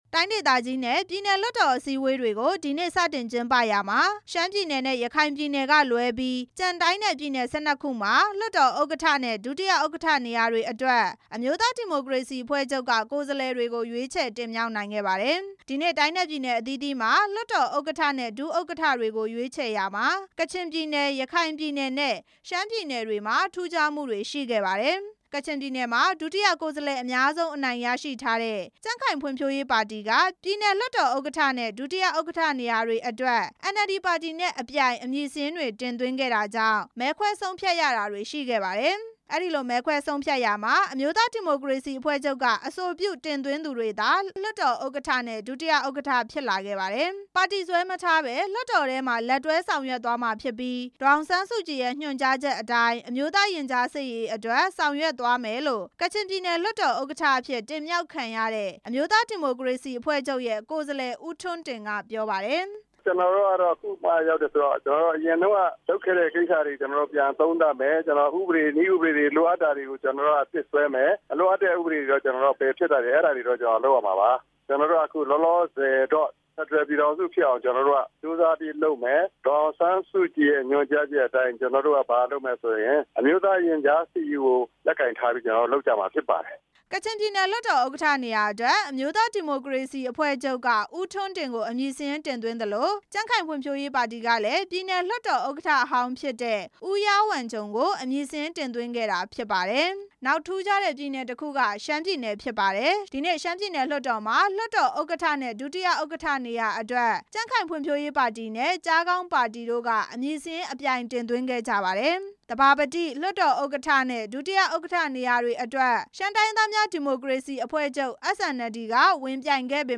တိုင်းနဲ့ ပြည်နယ်အသီးသီးက လွှတ်တော်အစည်းအဝေးထူးခြားဖြစ်စဉ်တွေနဲ့ လွှတ်တော်ဥက္ကဌနဲ့ ဒုတိယ ဥက္ကဌတွေရဲ့ စကားသံတွေကို အာအက်ဖ်အေ သတင်းထောက်